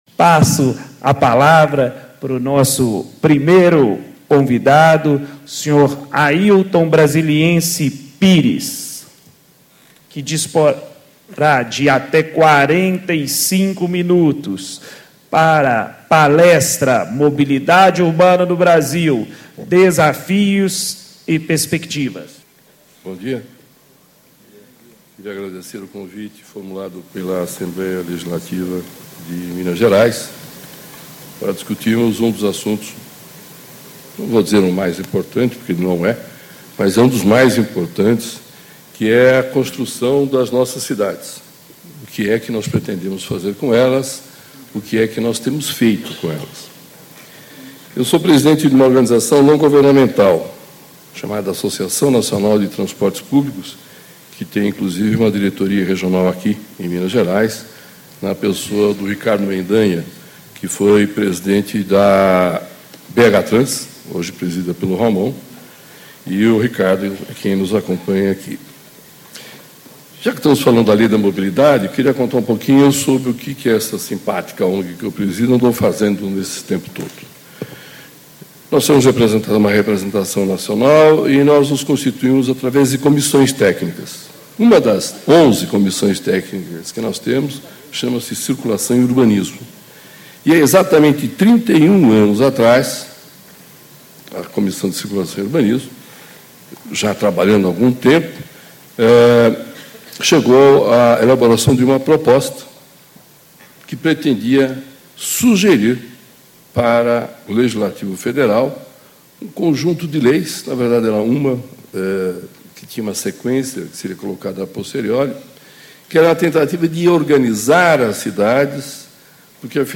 Palestra
Encontro Estadual do Fórum Técnico Mobilidade Urbana - Construindo Cidades Inteligentes